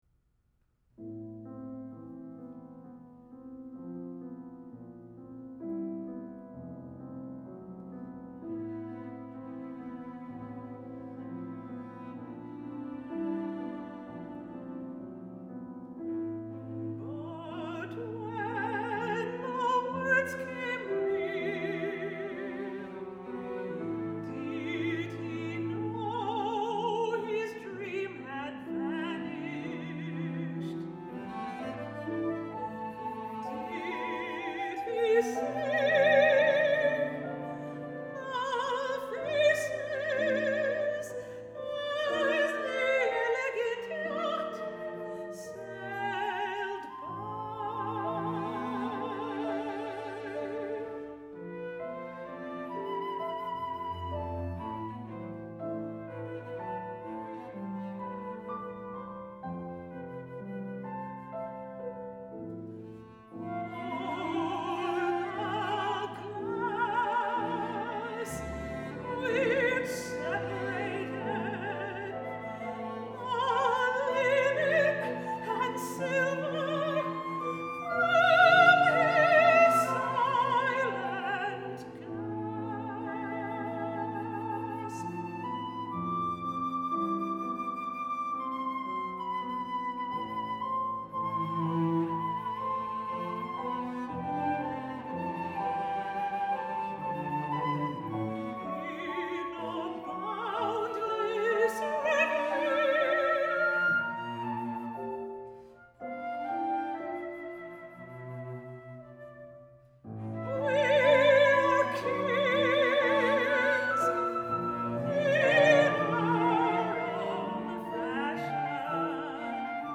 mezzo soprano
flute
cello
piano